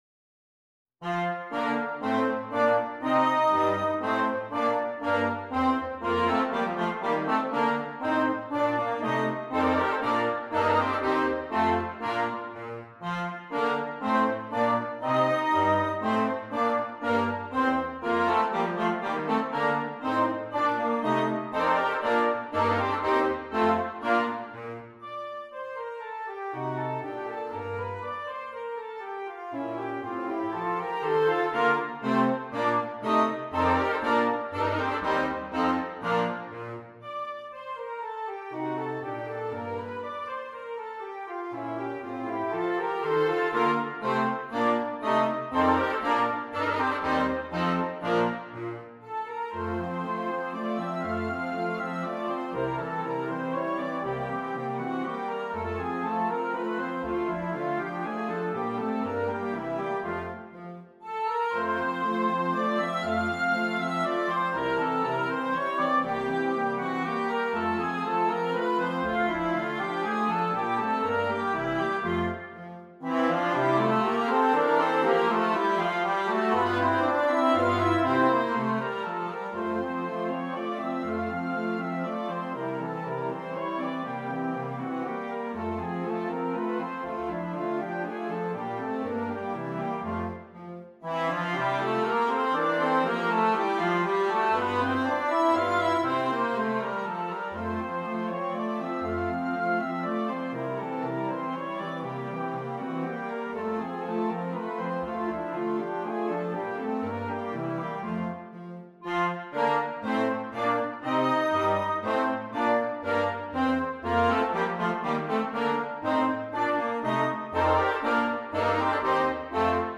Interchangeable Woodwind Ensemble
All parts are interesting and exciting.